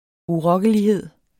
Udtale [ uˈʁʌgəliˌheðˀ ]